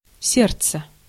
Ääntäminen
Ääntäminen UK GenAm: IPA : /hɑɹt/ US : IPA : [hɑɹt] RP : IPA : /hɑːt/ Tuntematon aksentti: IPA : /ˈhɑːrt/